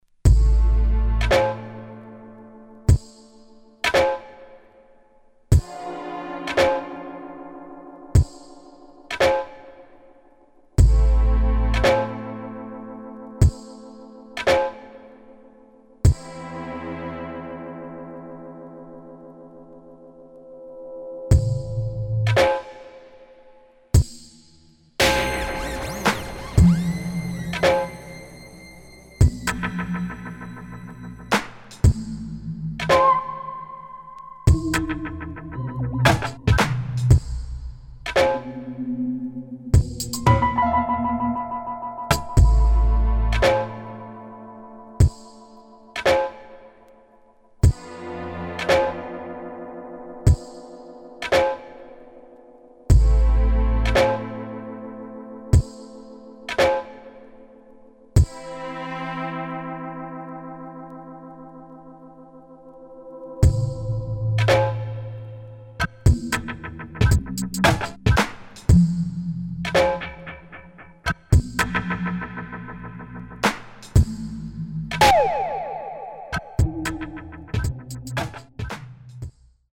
[ EXPERIMENTAL / DOWNBEAT ]